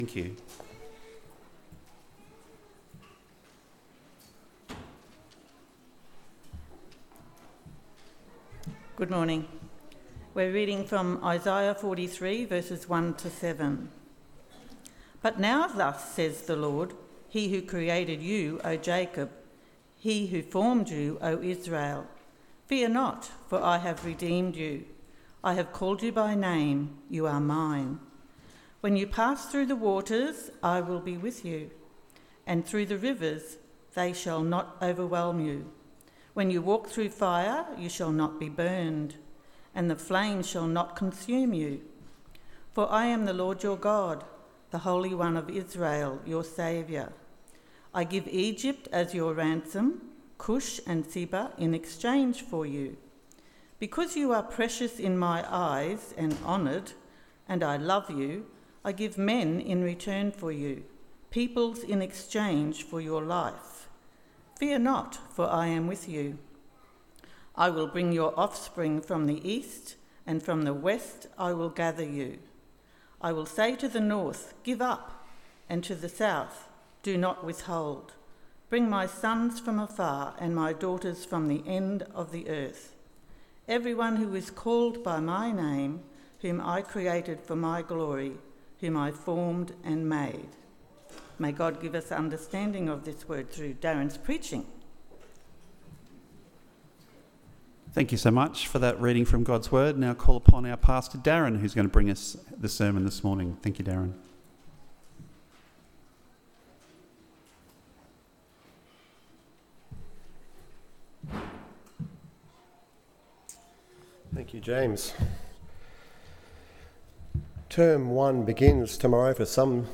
Fear Not … For God Is With Us AM Service